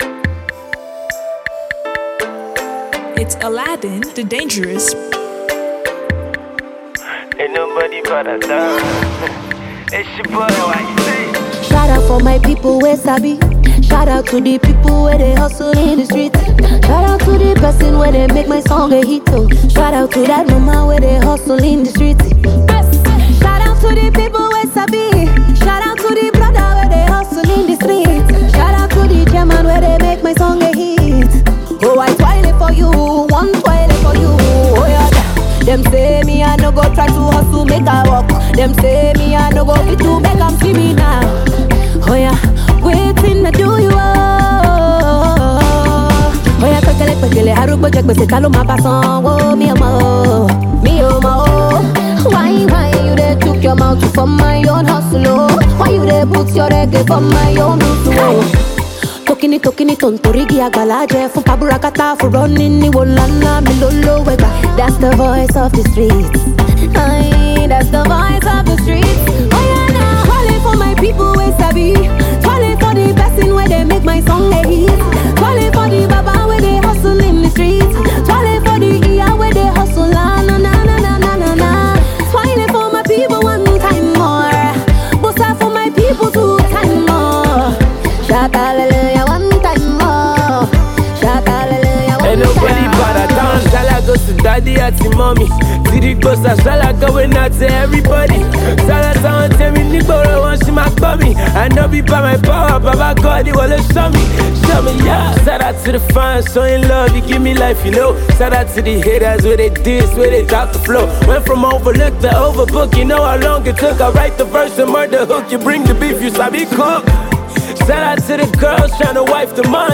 Nigeria Music
a tuneful pro-hard work record